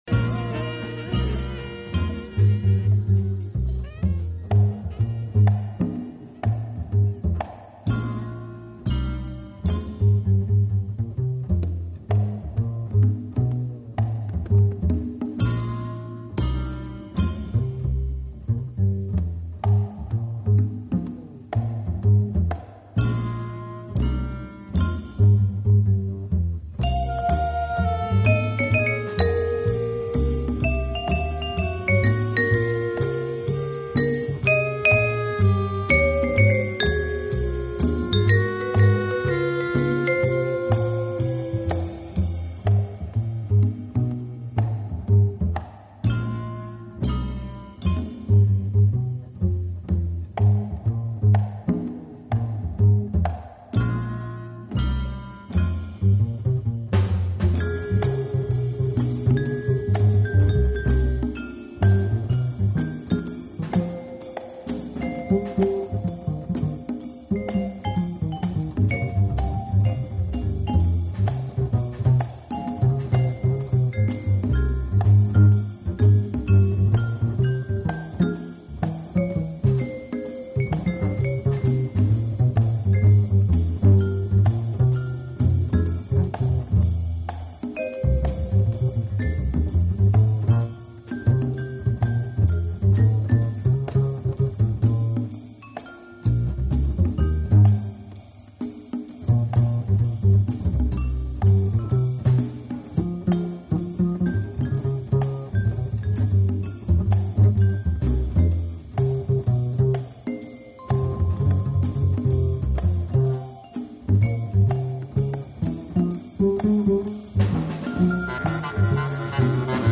【爵士休闲】
昏暗灯光中，音乐的色彩依然鲜明，曲风摇摆生姿，游走於真实与虚幻